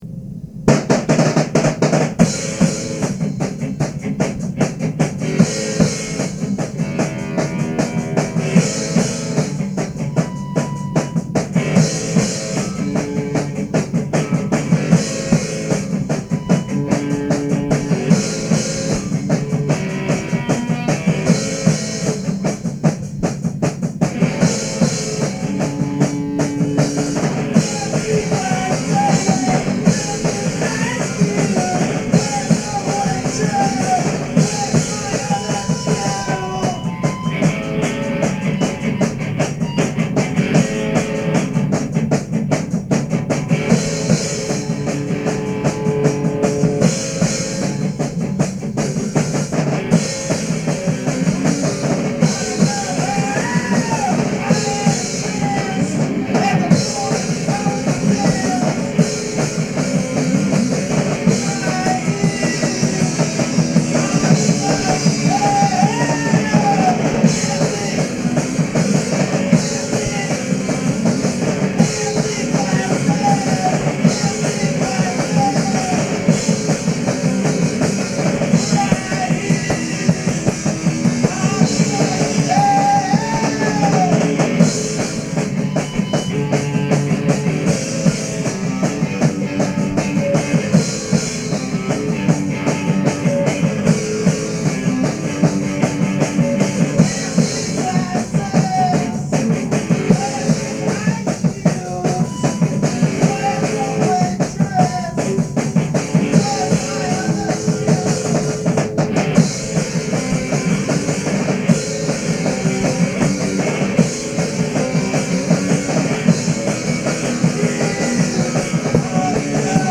cover songs
cassette (mono)